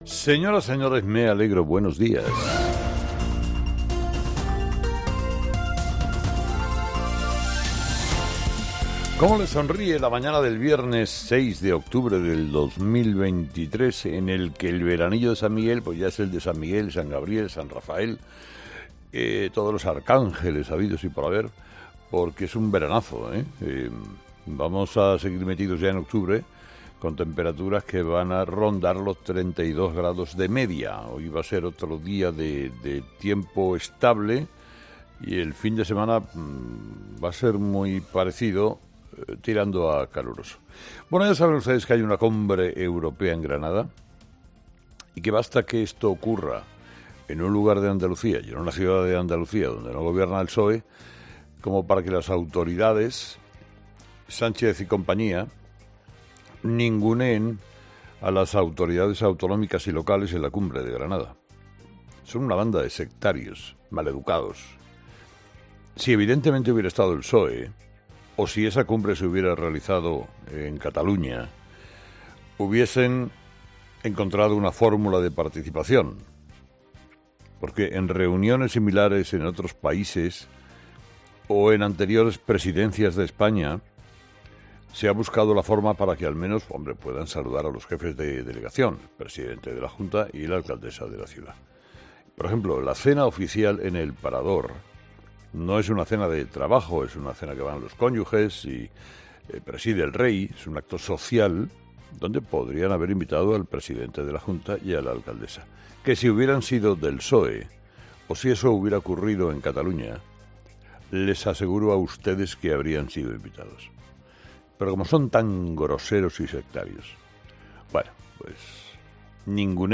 Escucha el análisis de Carlos Herrera a las 06:00 en Herrera en COPE el viernes 6 de octubre